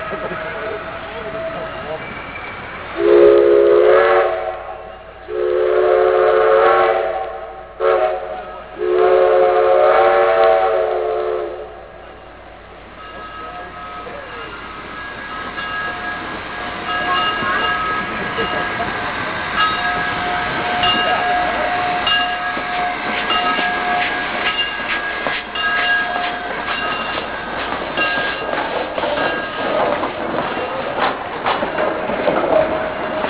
RAIL SOUNDS